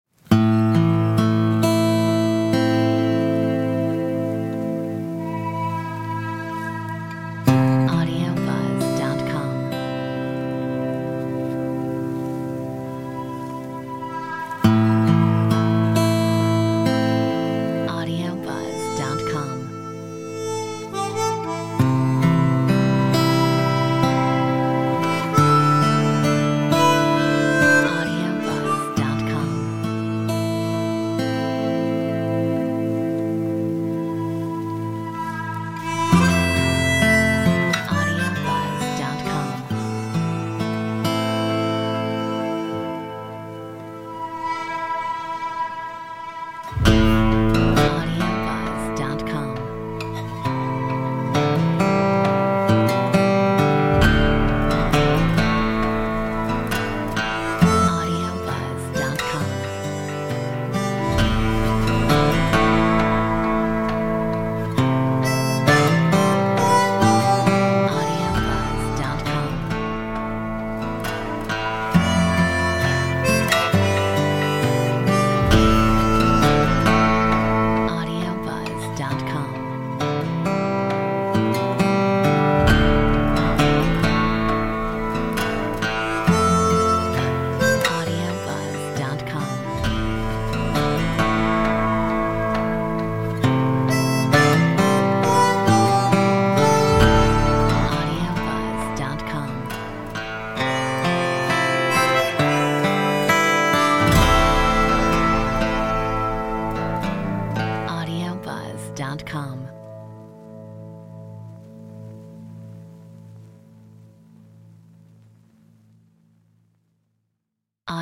Metronome 67